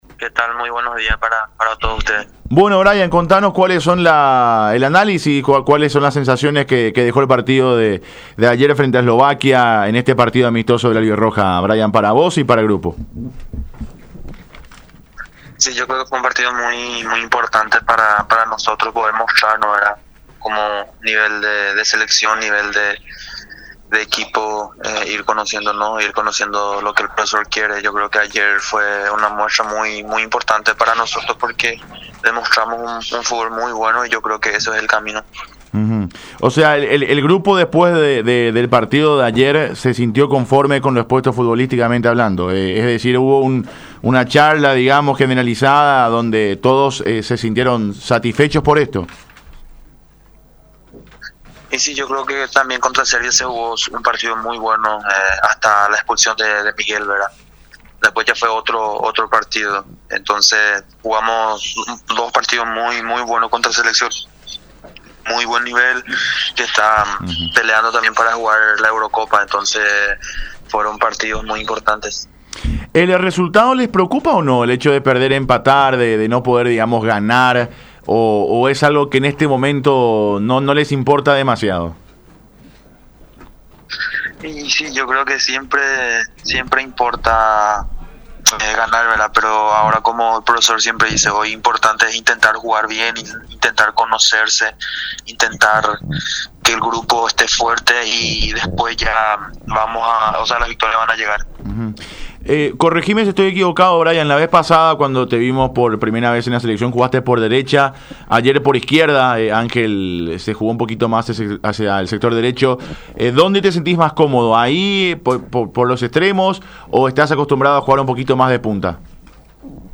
“El partido de ayer fue importante para nosotros para mostrar nuestro nivel. Demostramos un fútbol bueno”, expresó el atacante en diálogo con Unión FC, sin dejar de mencionar que como aspecto principal a mejorar tienen la contundencia frente al arco rival, más aún tomando en cuenta que Paraguay creó una enorme cantidad de ocasiones de gol, marcando solamente una vez.